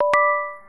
message.wav